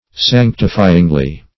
Search Result for " sanctifyingly" : The Collaborative International Dictionary of English v.0.48: Sanctifyingly \Sanc"ti*fy`ing*ly\, adv.